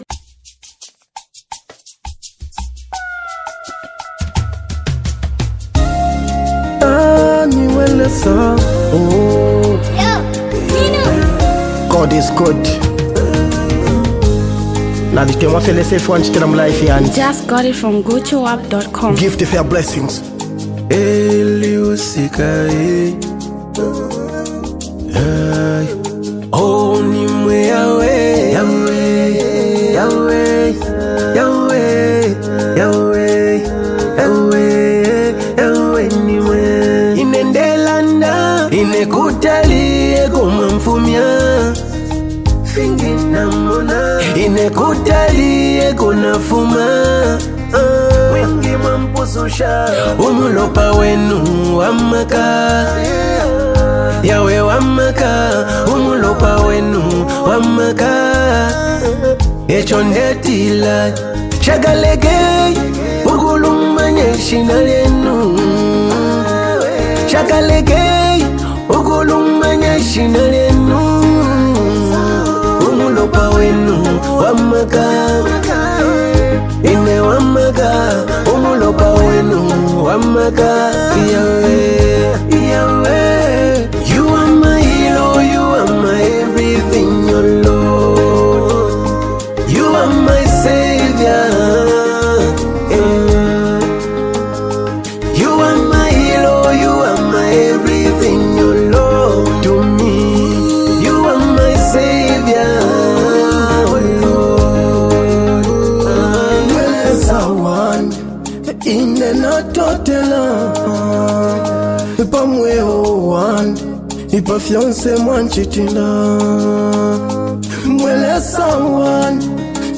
is a powerful gospel jam
soulful vocals
Zambian gospel Inspirational songs